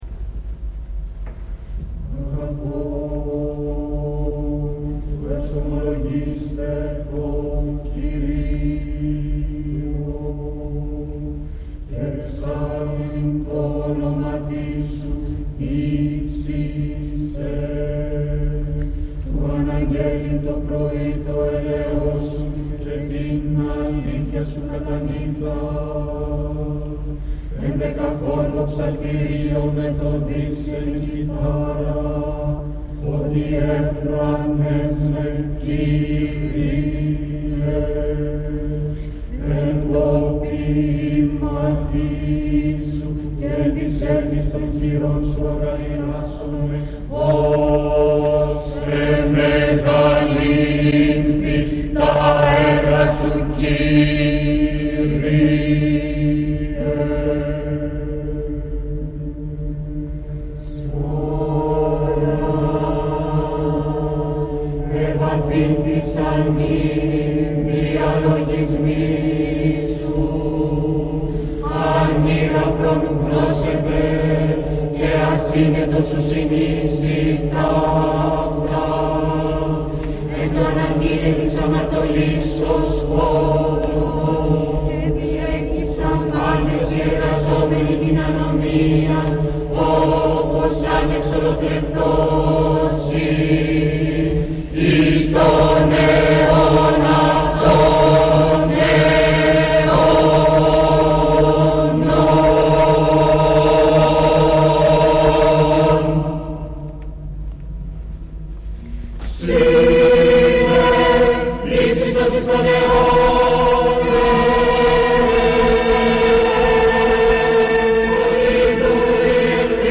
Συναυλία στην Ροτόντα (1976)